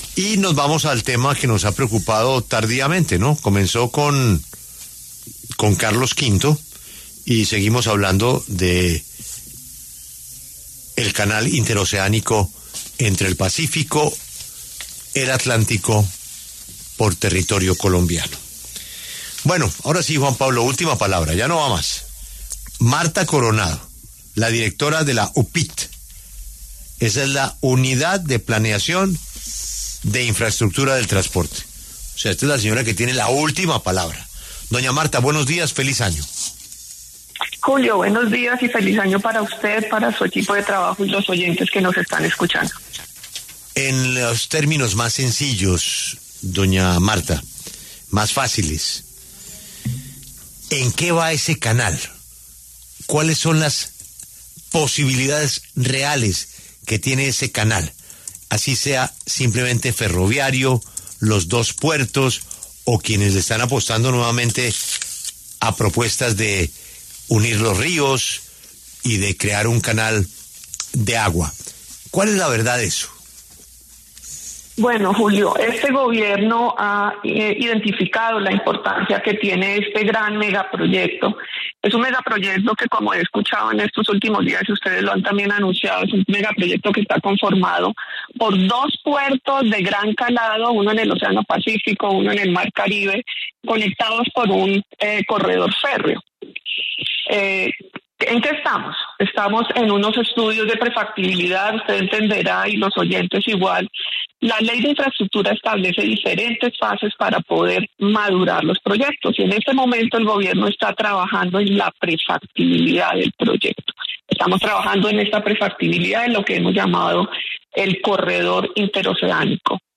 La directora general de la UPIT, Martha Constanza Coronado, habló en La W, con Julio Sánchez Cristo sobre el canal interoceánico en el Chocó.